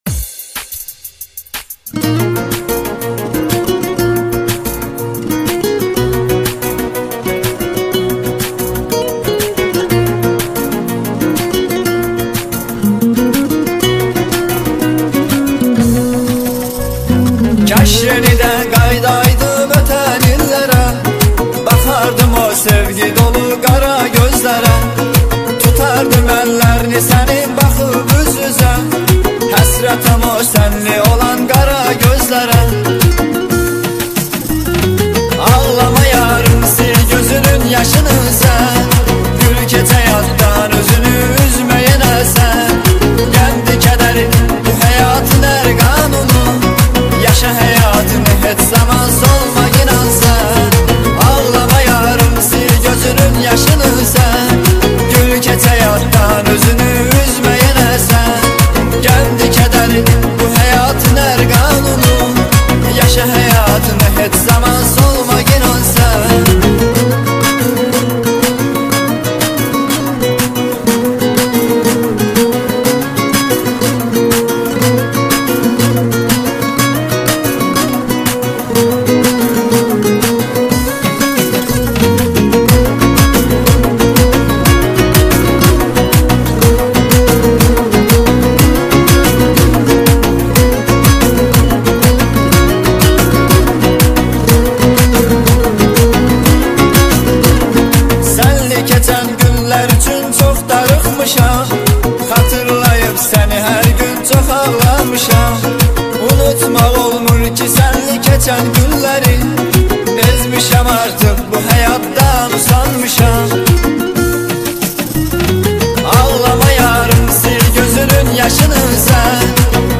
Турецкая музыка